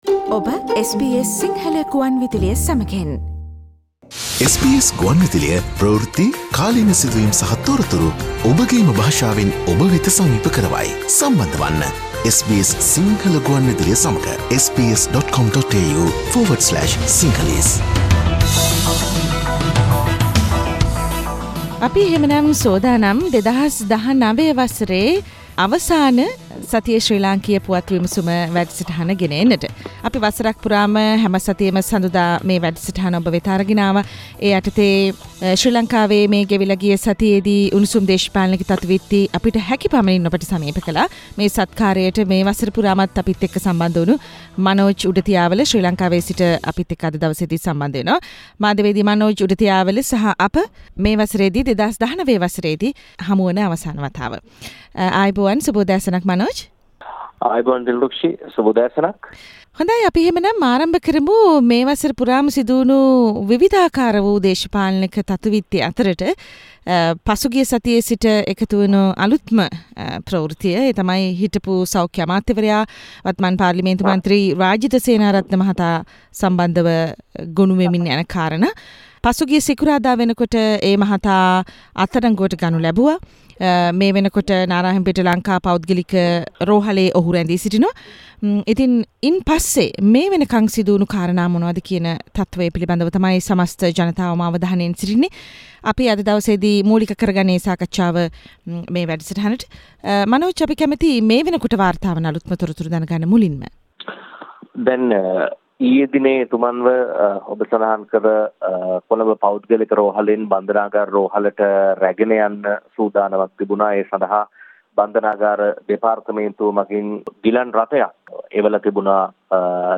රාජිත තවමත් බන්ධනාගාර රෝහලට නොයාමට හේතු සහ නව වර්ෂයේ නව පාර්ලිමේන්තුව සඳහා සුදානම ඇතුළු තතු : සතියේ ශ්‍රී ලාංකීය දේශපාලන පුවත් විග්‍රහය
සතියේ දේශපාලන පුවත් සමාලෝචනය